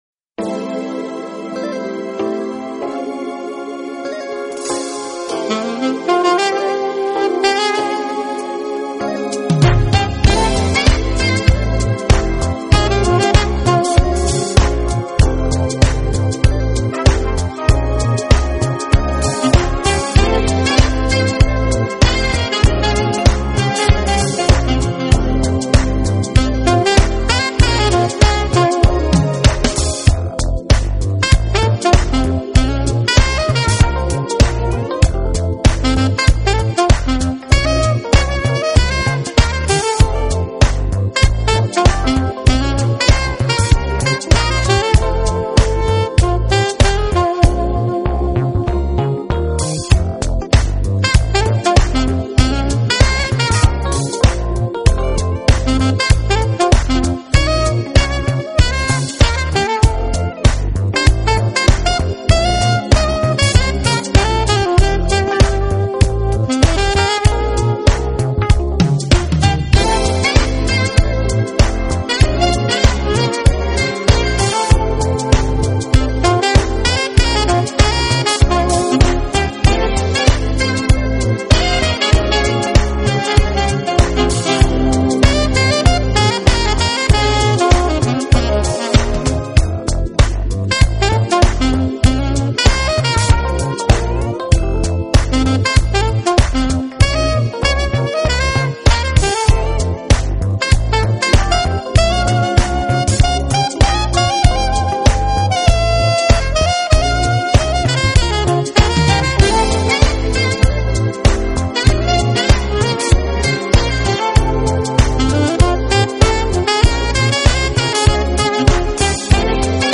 音乐类型：Smooth Jazz
些，而且节奏都属比较明快，而SAX又很悠扬的那种。
soprano saxophone
alto saxophone, vocoder
trumpet
trombone
keyboards, programming
guitar
bass
drums
percussion
background vocals
Recorded at East Bay Music Recording, Tarrytown, New York.